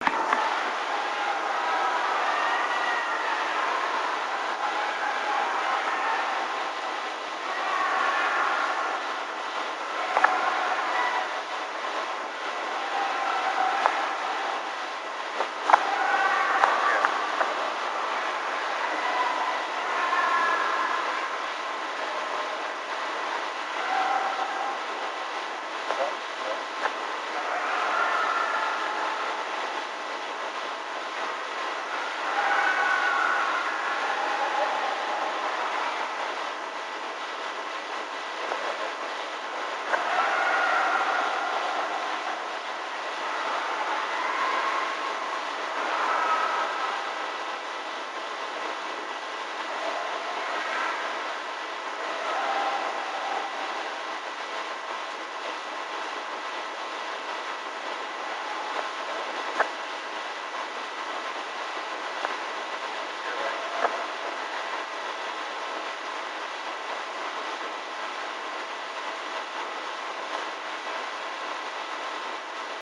File:New mexico scream.mp3 - Squatchopedia 2.0
New_mexico_scream.mp3